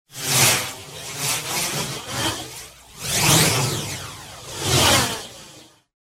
Звуки движения, переходов
Завихрение